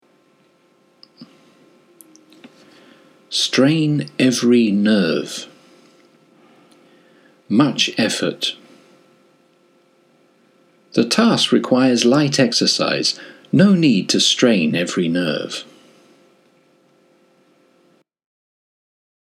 緊張しすぎると力がうまく発揮できないのではないかなと思うのですが、英語のイディオムとしては「全力を尽くす、精いっぱいの力で努める」という意味になります。 英語ネイティブによる発音は下記のリンクをクリックしてください。